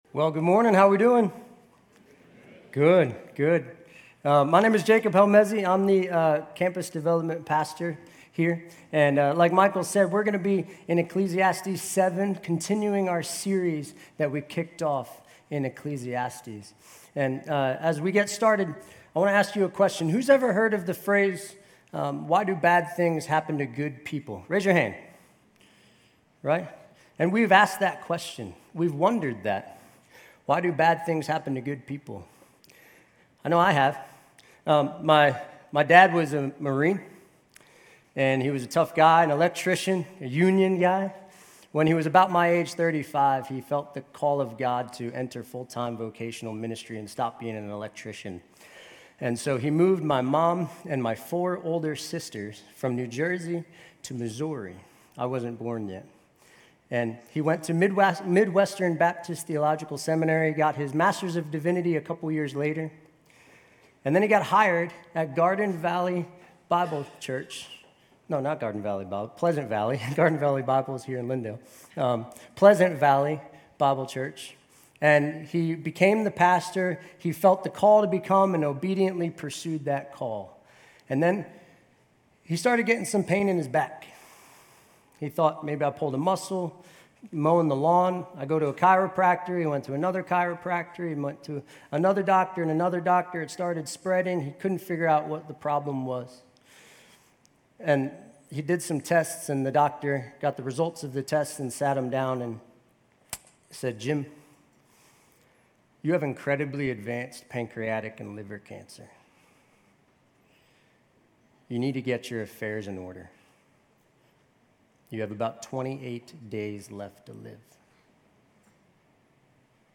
GCC-UB-May-21-Sermon.mp3